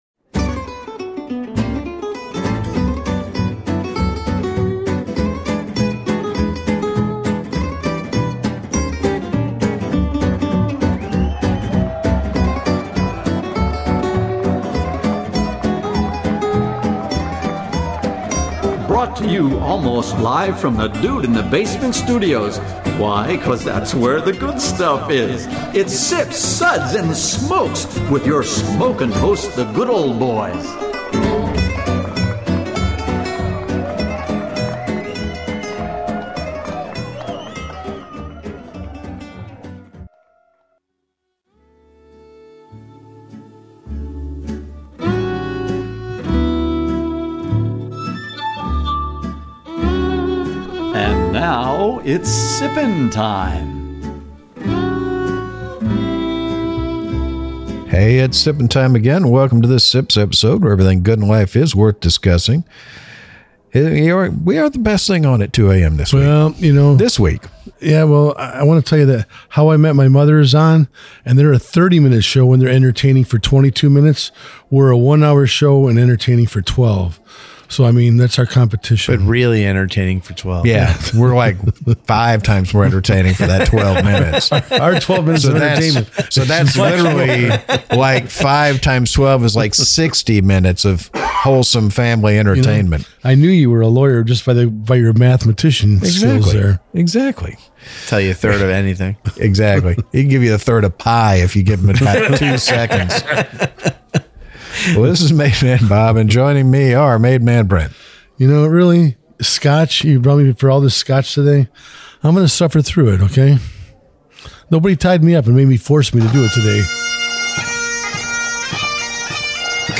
Radio MP3 with Music Beds
Location Recorded: Nashville,TN